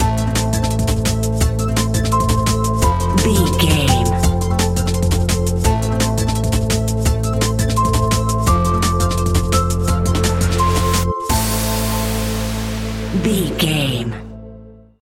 Aeolian/Minor
Fast
aggressive
groovy
futuristic
industrial
frantic
drum machine
synthesiser
electronic
sub bass
synth leads